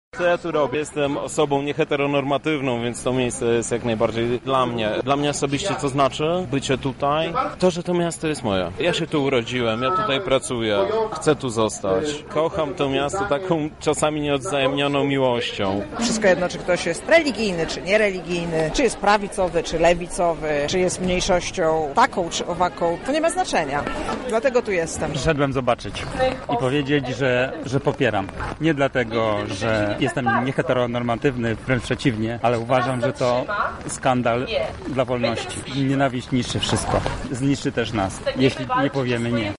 Sonda